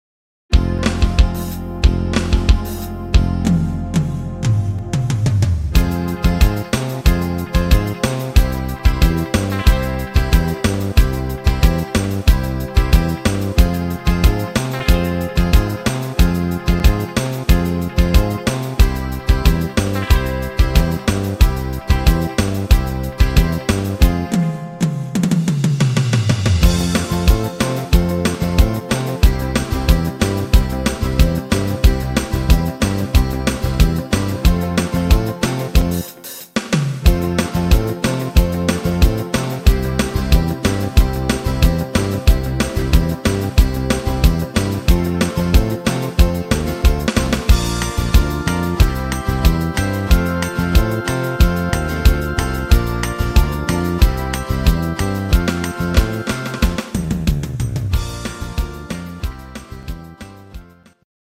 Instrumental für Gitarre